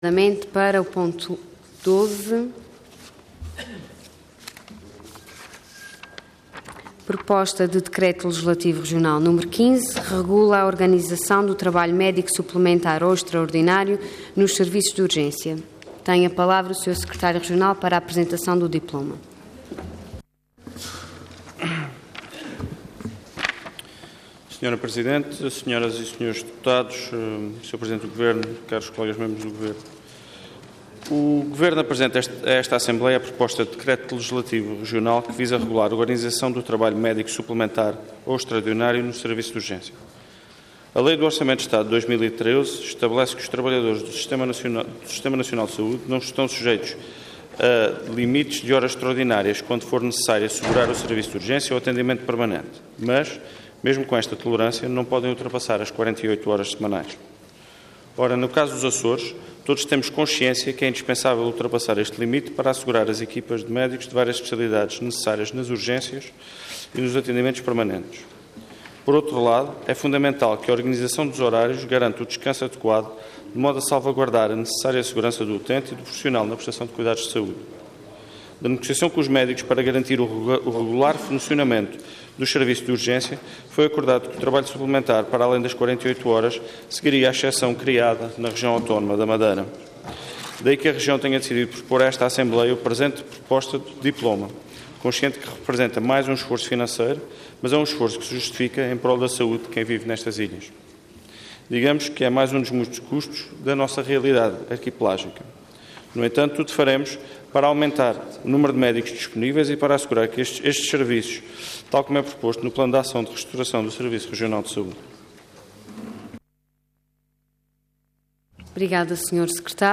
Website da Assembleia Legislativa da Região Autónoma dos Açores
Detalhe de vídeo 5 de setembro de 2013 Download áudio Download vídeo Diário da Sessão Processo X Legislatura Regula a organização do trabalho médico suplementar ou extraordinário nos Serviços de Urgência. Intervenção Proposta de Decreto Leg. Orador Luís Cabral Cargo Secretário Regional da Saúde Entidade Governo